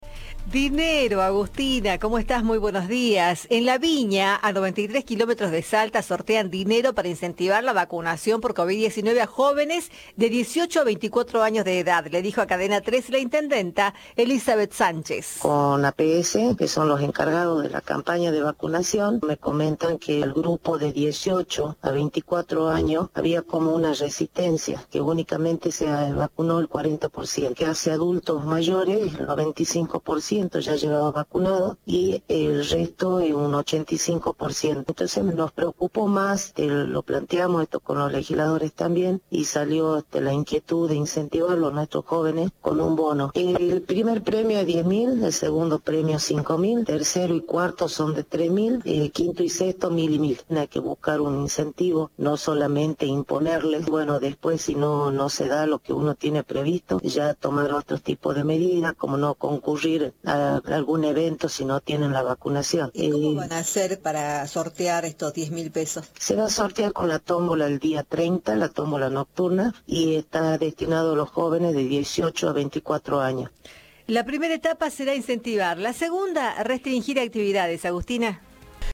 La intendenta de La Viña indicó a Cadena 3 que la iniciativa apunta a la población de 18 a 24 años.